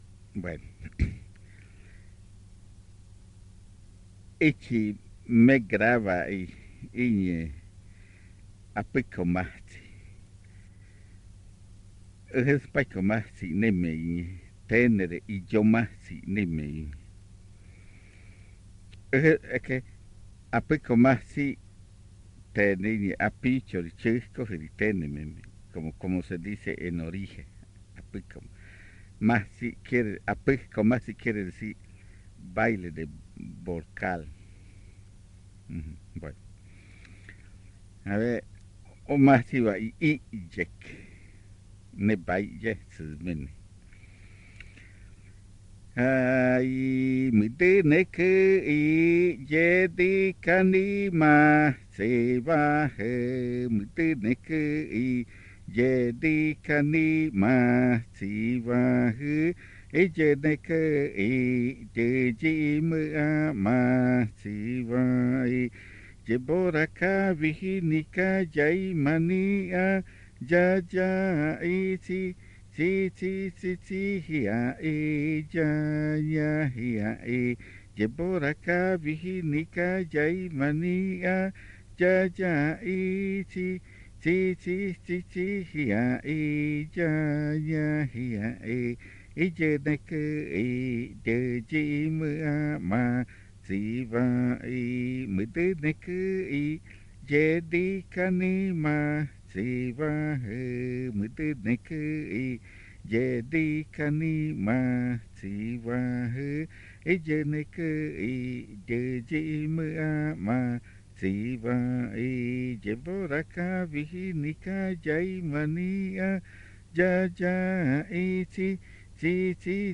Canciones del baile de Illo majtsi (chucula)
La grabación fue hecha en casete
El audio incluye los lados A y B del casete.